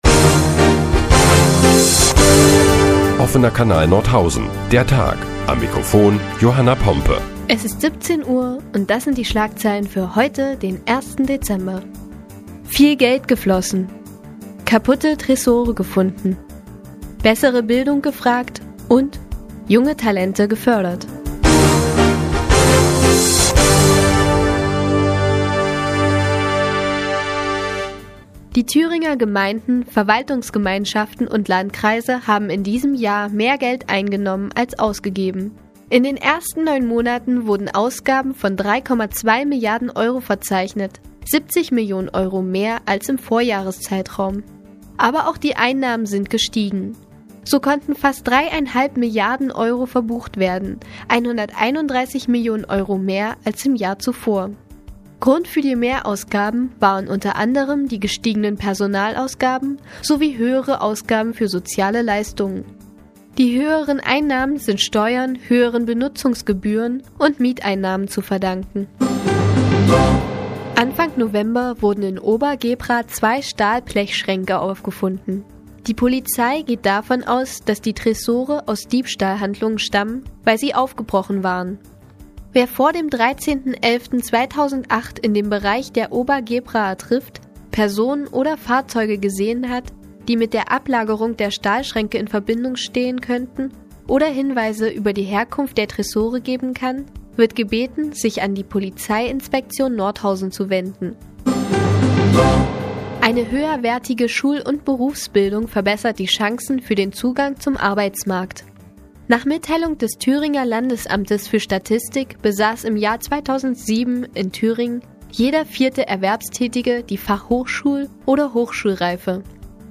Die tägliche Nachrichtensendung des OKN ist nun auch in der nnz zu hören. Heute unter anderem mit einem Tresorenfund und einer Ausstellungseröffnung.